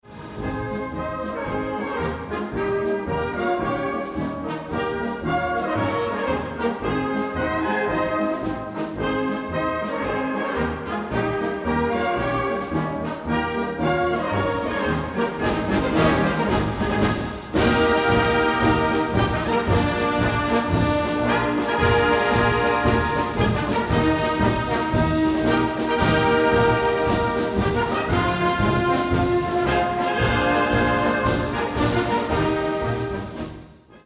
Hörproben & Musikliteratur | Musikkapelle Oberbozen EO am Ritten
kaerntner-liedermarsch.mp3